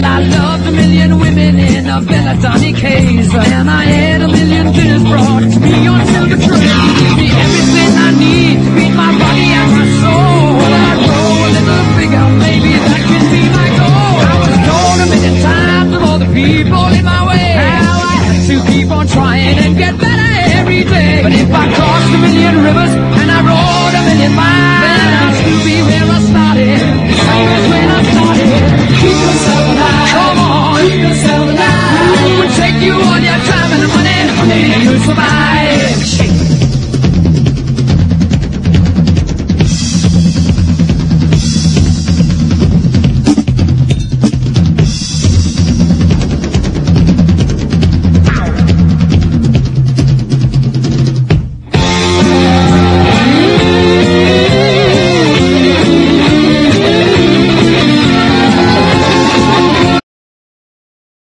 ROCK / 70'S